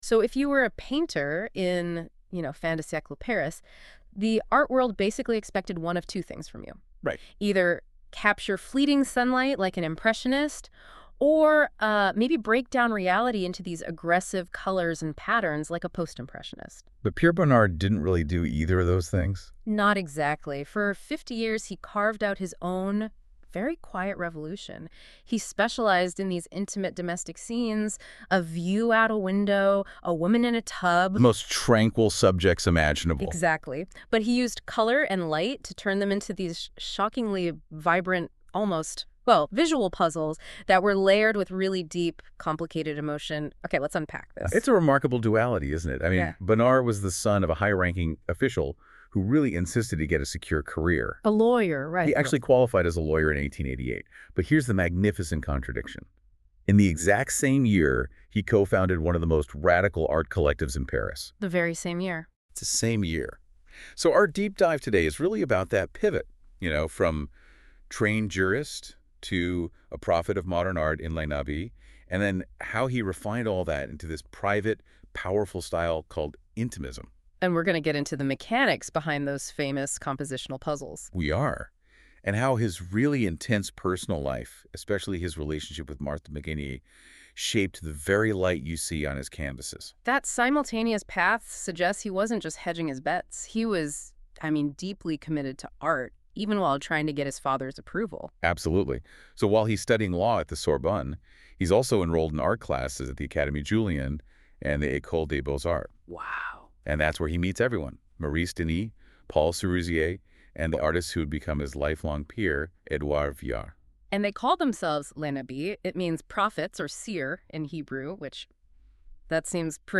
A discussion on Post Impressionism 1880-1905 (created by NotebookLM from my notes): Pierre Bonnard